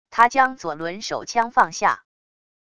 他将左轮手枪放下wav音频